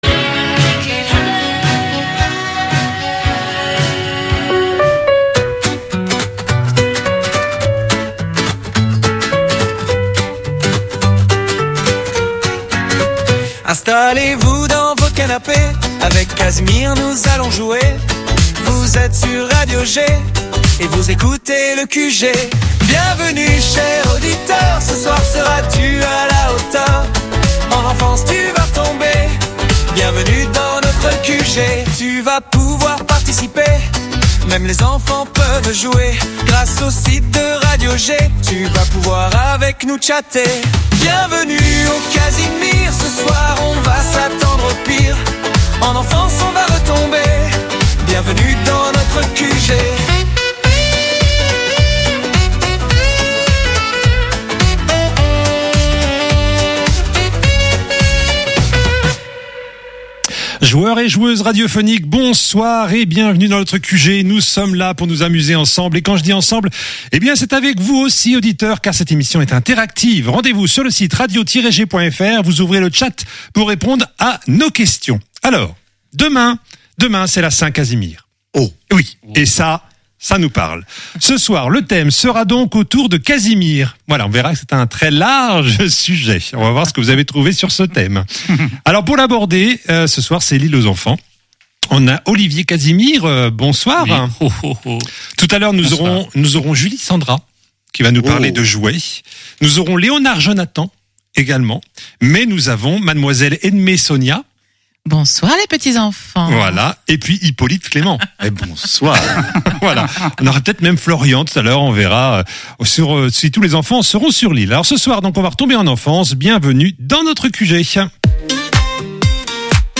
Le QG, le programme radio de jeux de Radio G!